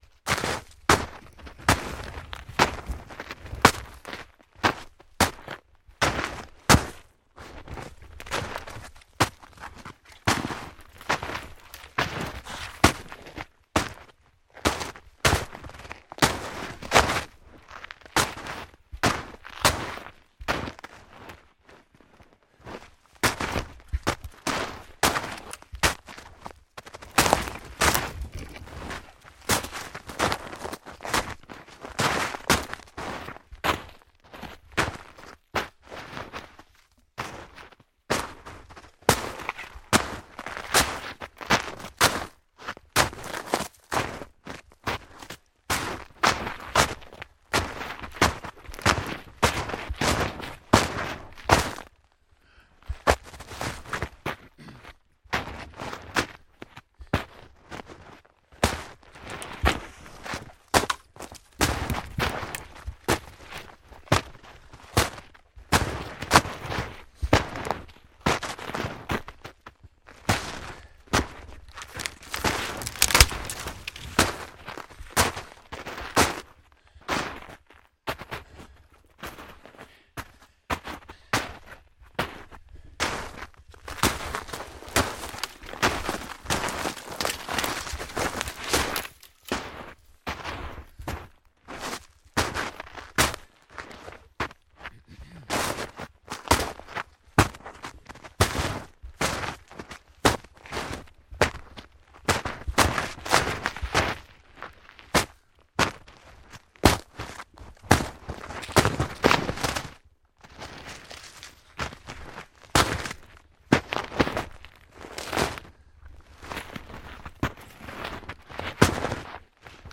冬天" 脚步声 沉重的橡胶靴 森林深处融化的结痂的雪 沉重的缓慢的中等速度的一些树枝在树木之间折断
描述：脚步声沉重的橡胶靴森林深厚的硬壳暴雪厚重中等速度树枝之间的一些分支快照.flac
Tag: 脚步 靴子 森林 橡胶